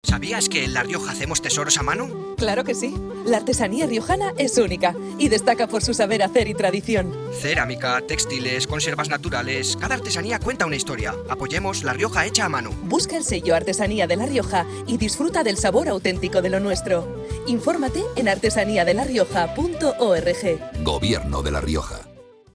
Cuña radiofónica